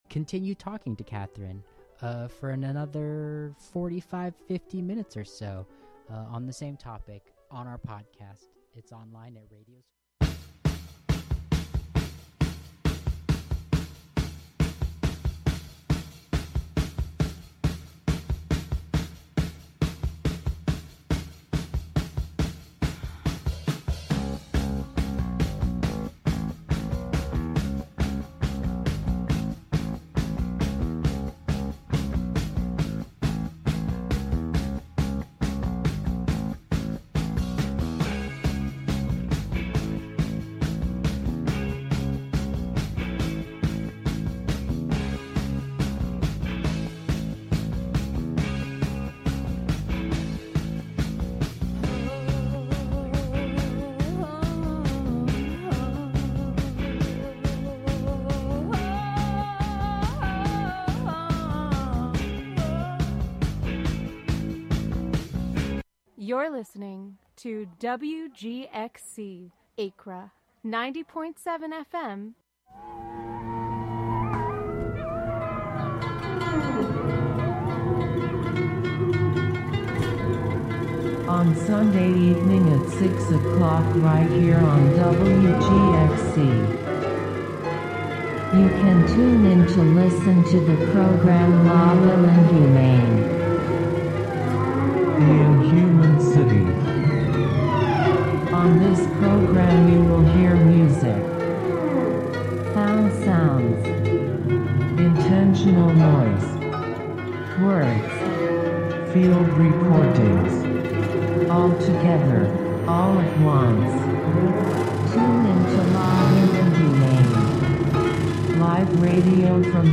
The show is a place for a community conversation about issues, with music, and more. Saturday the emphasis is more on radio art, and art on the radio.